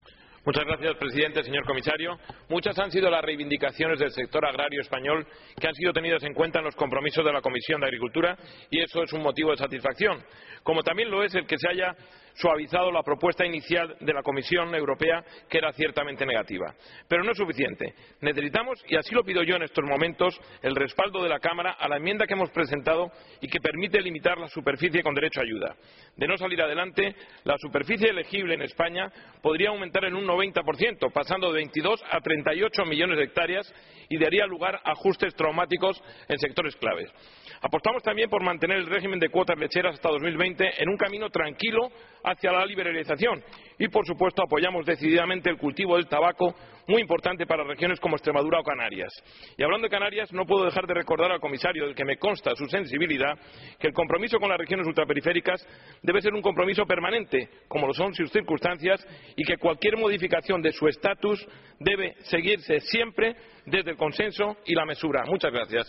El eurodiputado del Partido popular Gabriel Mato ha intervenido en el debate sobre la reforma de la Política Agraria Común (PAC) celebrado por el Pleno del Parlamento Europeo, para demandar a la Comisión Europea que garantice el futuro del sector agrario del Archipiélago y del resto de regiones ultraperiféricas, tomando en consideración sus especiales circunstancias.